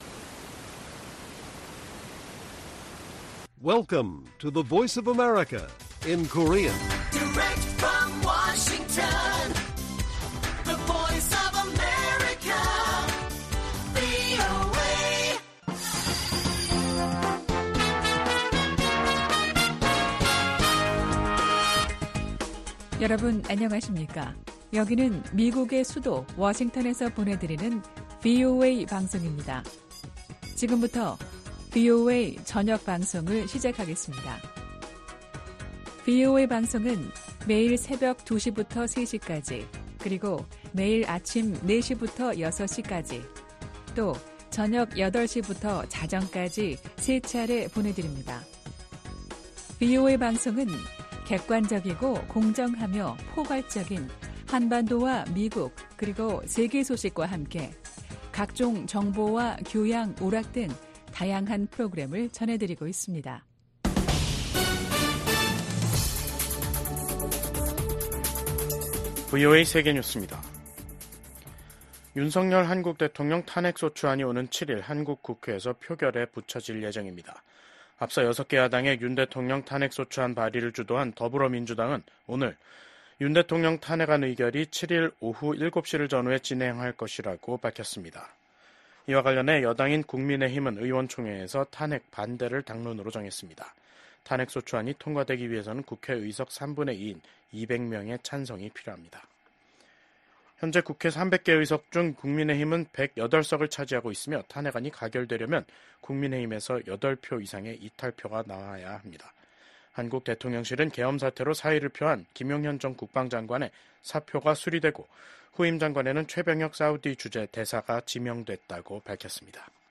VOA 한국어 간판 뉴스 프로그램 '뉴스 투데이', 2024년 12월 5일 1부 방송입니다. 한국 6개 야당이 비상계엄 선포와 관련해 발의한 윤석열 대통령 탄핵소추안이 7일 국회에서 표결에 부쳐질 예정입니다. 미국 국무장관이 한국은 전 세계에서 가장 모범적인 민주주의 국가 중 하나라면서 윤석열 한국 대통령의 비상계엄 해제 결정을 환영했습니다.